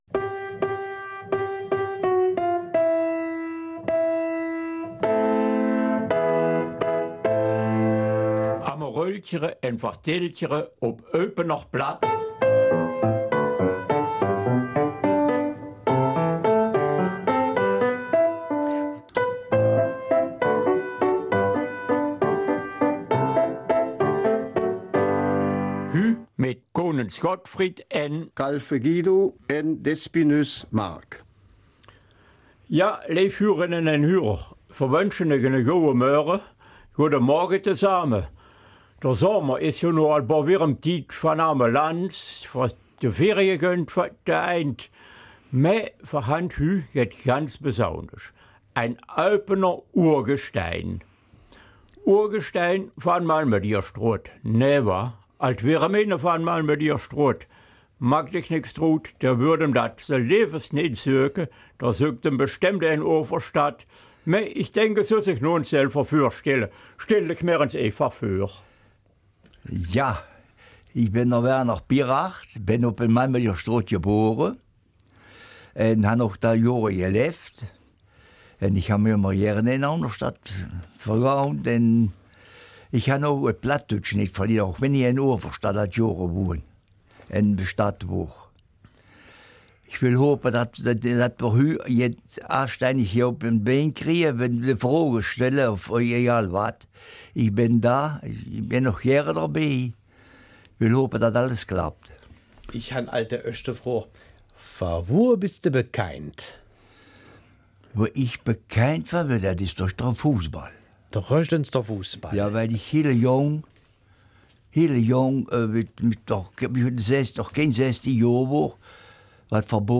Eupener Mundart: Ein Teamplayer gibt sich die Ehre